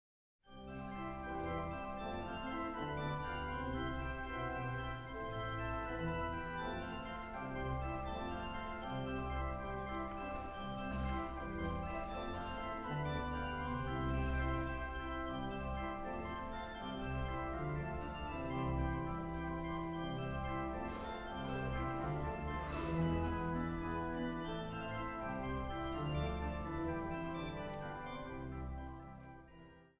Massale niet ritmische samenzang
vanuit de Cunerakerk te Rhenen
orgel.